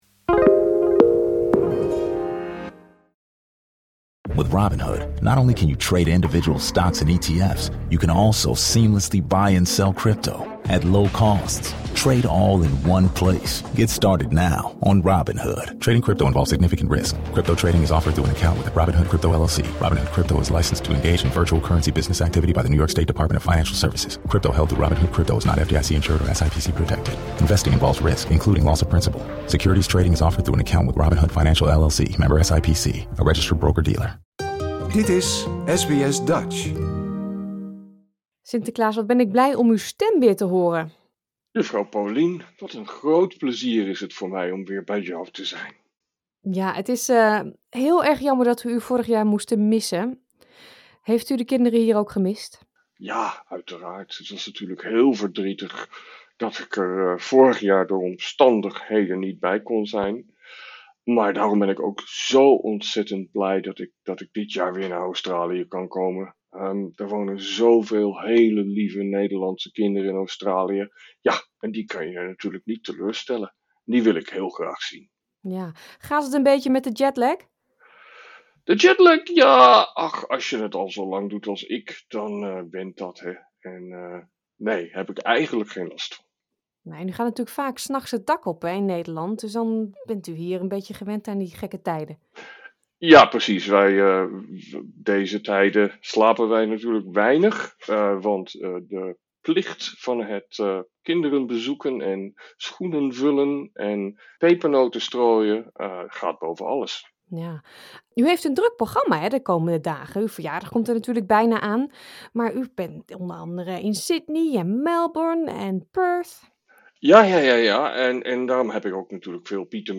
Na een jaar van afwezigheid is de Goedheiligman erg blij dat hij en de pieten de Nederlandse kinderen in Australie weer blij kan maken met pepernoten en cadeautjes. Ondanks zijn drukke schema, had hij tijd om enkele vragen van jonge luisteraars te beantwoorden.